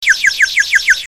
clock11.mp3